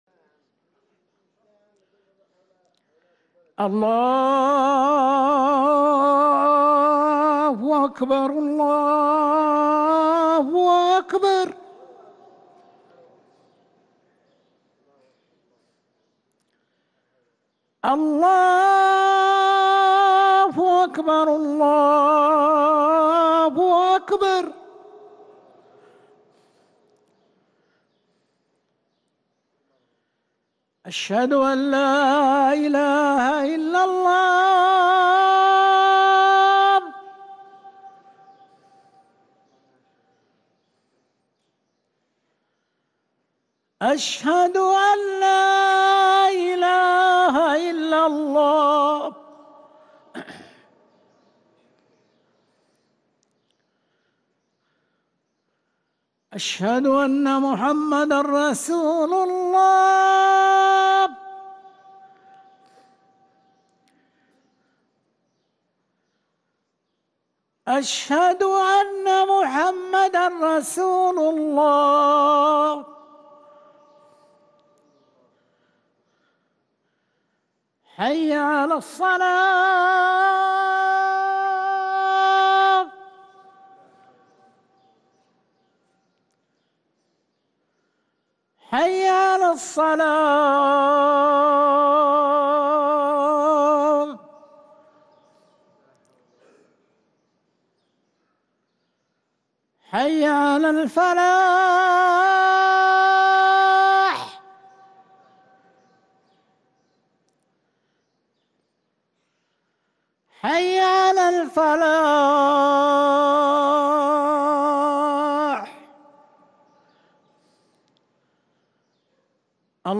اذان العصر
ركن الأذان